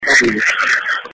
EVP's